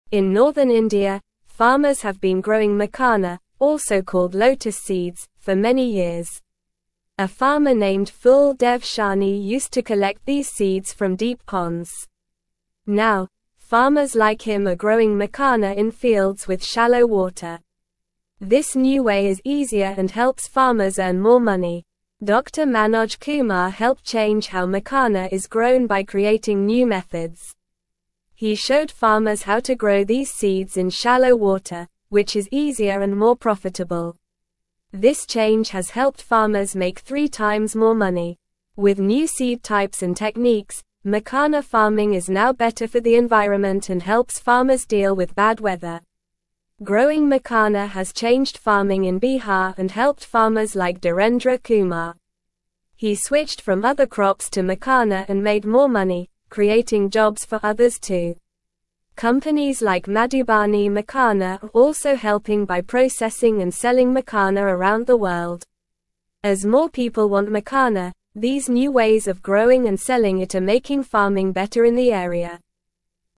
Normal
English-Newsroom-Lower-Intermediate-NORMAL-Reading-Growing-Makhana-Seeds-Helps-Farmers-Earn-More-Money.mp3